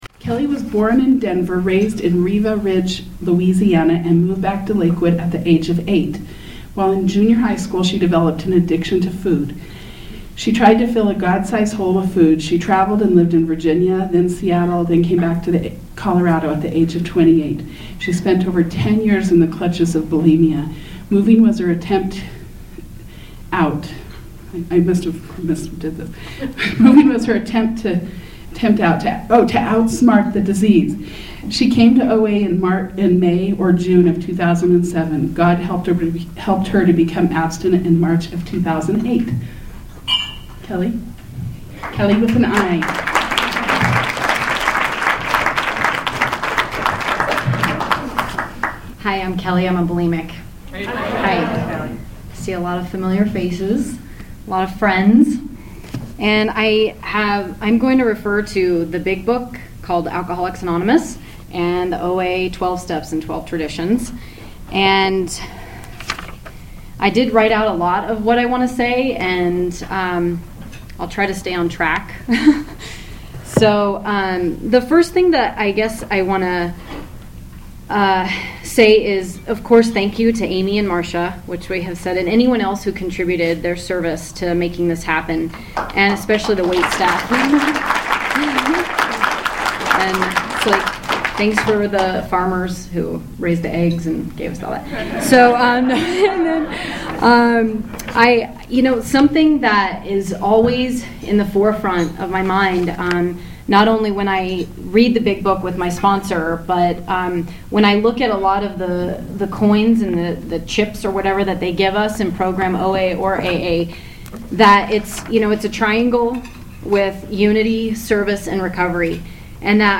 shares her story of recovery at the 2011 OA Brunch.
at OA’s Ninth Annual New Years Day brunch in Lakewood.